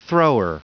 Prononciation du mot thrower en anglais (fichier audio)
Prononciation du mot : thrower